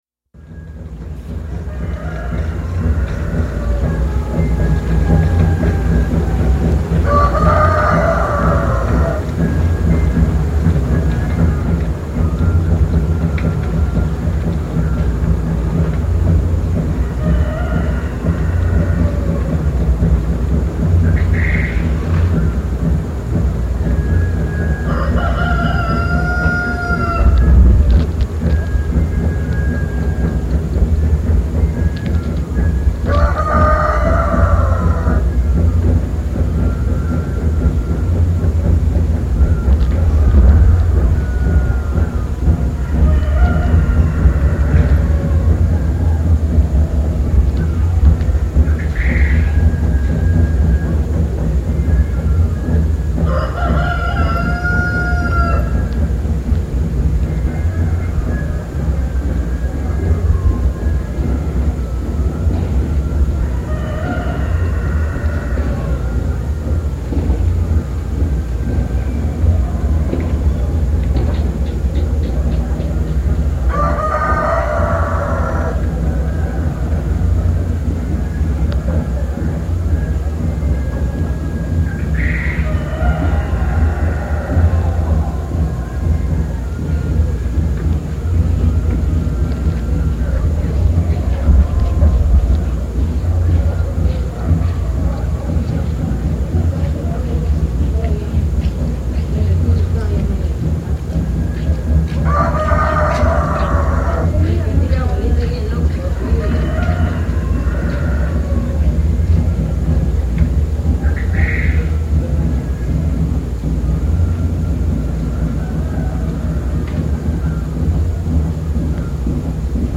Te invito a estar en el pueblo de Suchiapa que se encuentra a 20 Kms. de la ciudad capital de Chiapas, Tuxtla Gutierrez. Recomiendo el uso de los audífonos para una mayor experiencia sonora. El presente registro se podría ubicar como un paisaje sonoro Hi-fi.